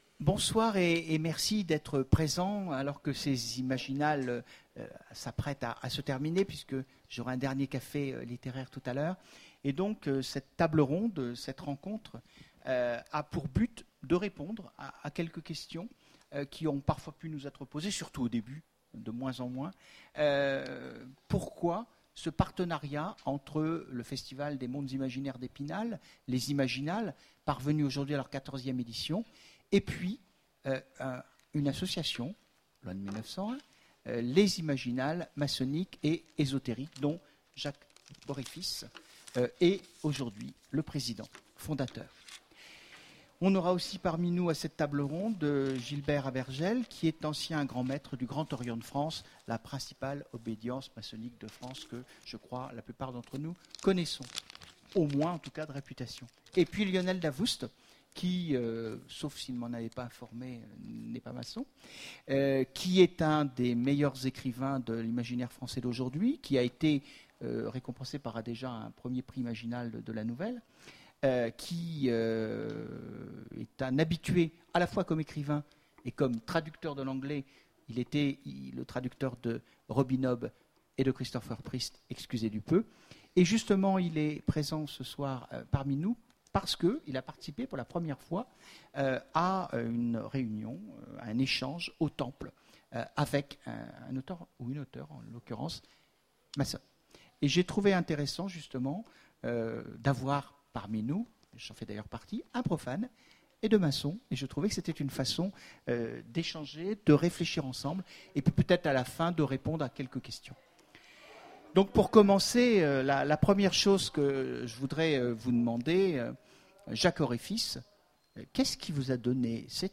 Imaginales 2015 : Conférence Pourquoi les Imaginales maçonniques ?